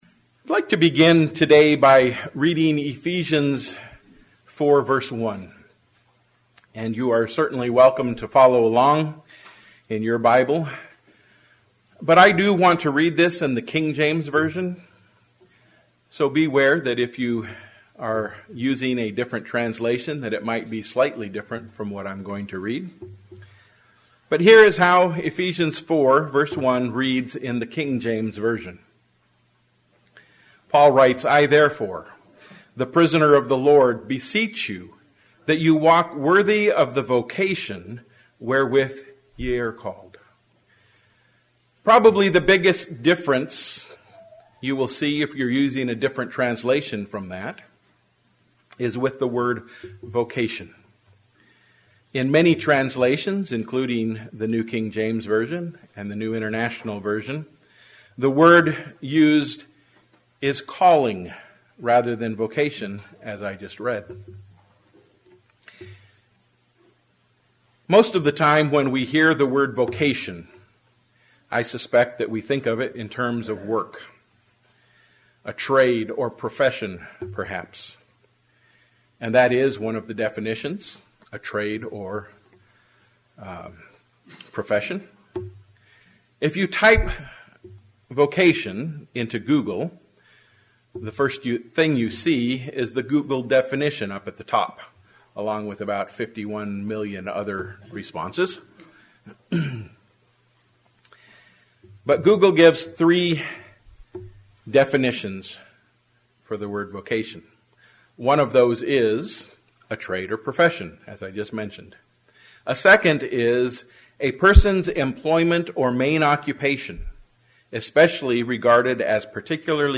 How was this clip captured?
Given in Flint, MI